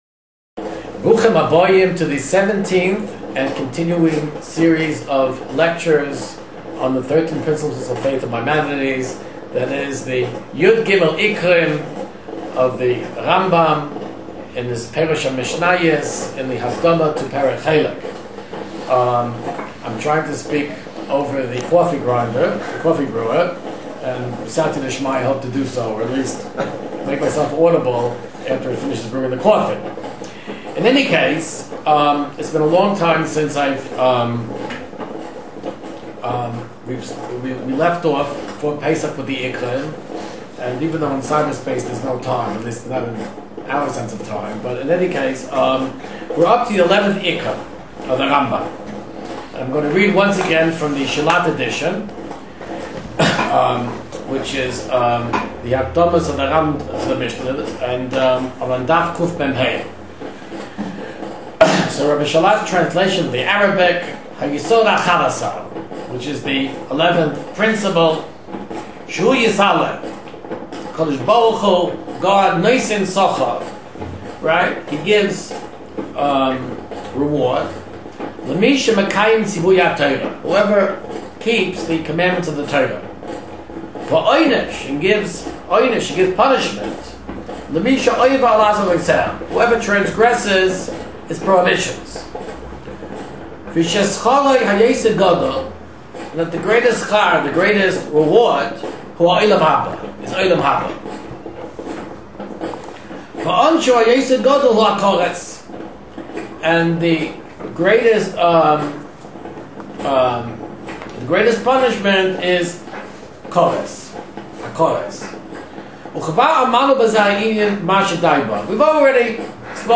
This shiur discusses the 11th of Rambam’s principles. The principle is the belief that G-d rewards and punishes people in Olam HaBa (or by lack thereof).